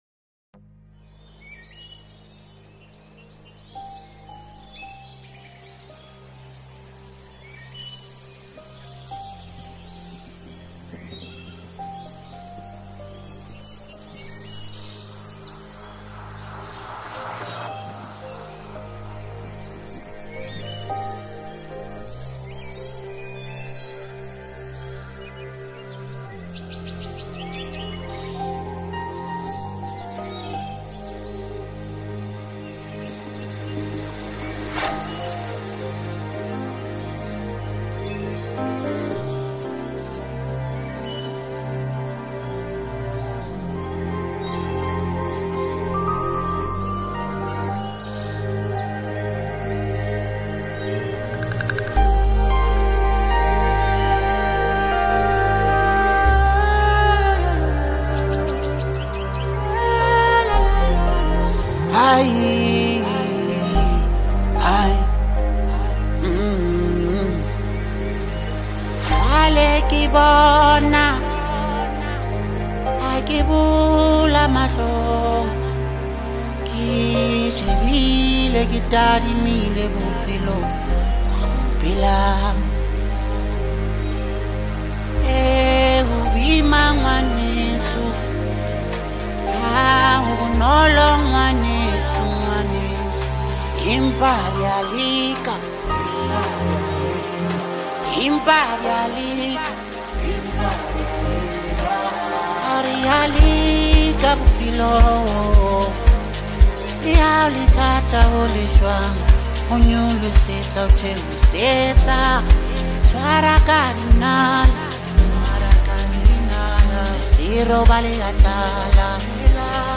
mixtape
amapiano beats